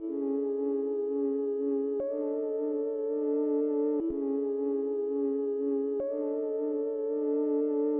标签： 120 bpm Trap Loops Organ Loops 1.35 MB wav Key : D
声道立体声